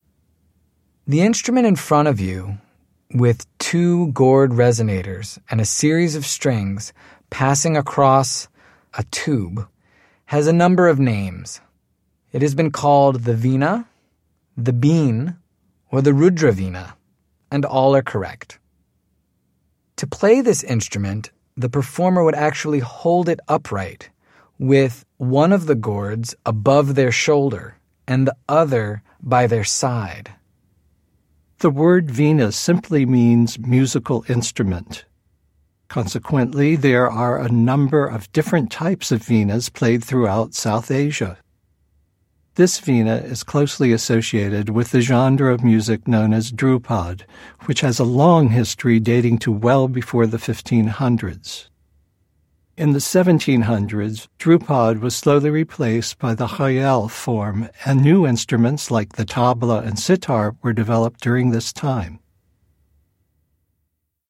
Vīnā (bīn)
Bīn, a term with various meanings throughout India, refers to this fretted stick zither in north (Hindustani) India.
The bīn, is held at an angle with the pegs positioned above the left shoulder and the lower end lying within easy reach of the right hand which plucks the strings.